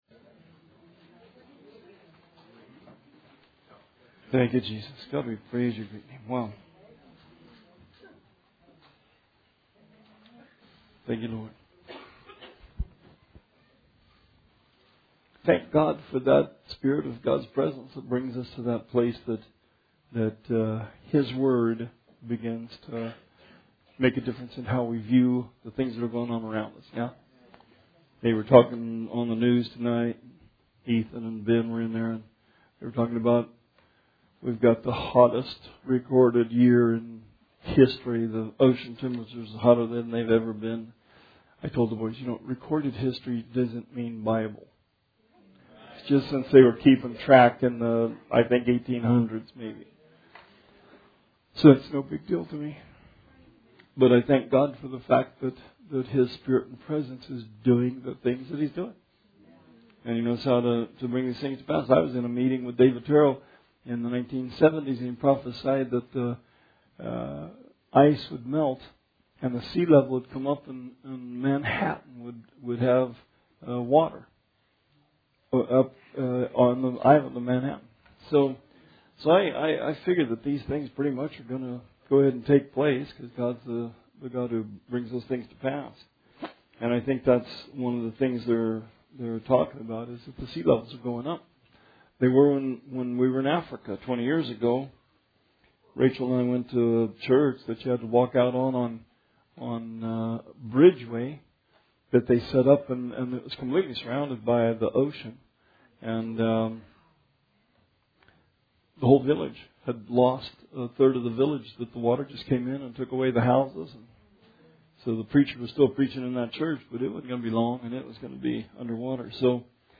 Bible Study 1/15/20